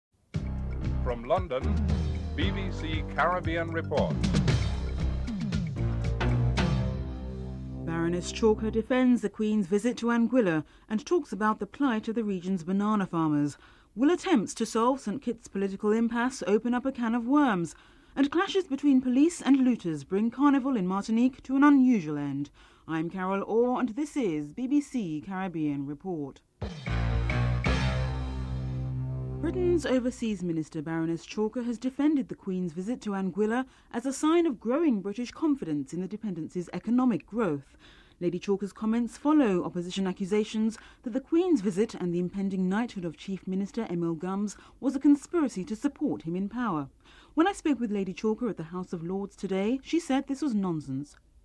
1. Headlines (00:00-00:27)
7. Wrap up and theme music (14:39-15:07)